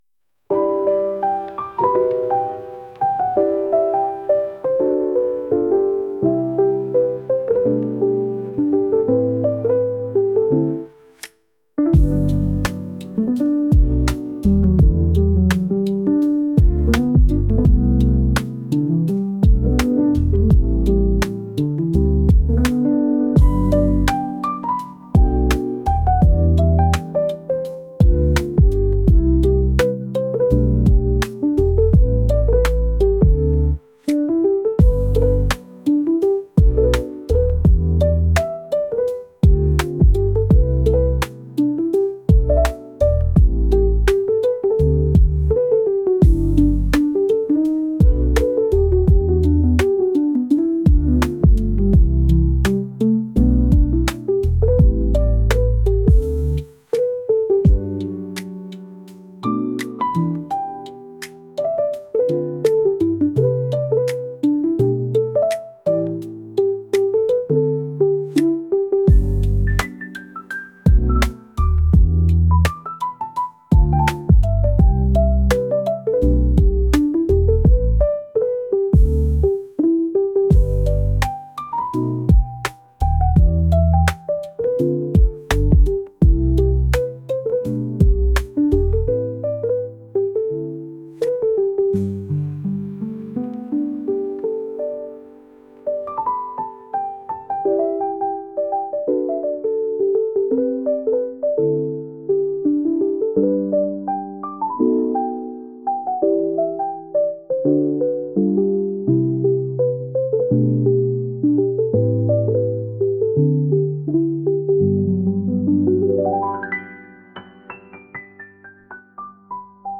ゆったりと好きな人を待っているような音楽です。